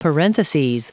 Prononciation du mot parentheses en anglais (fichier audio)
Prononciation du mot : parentheses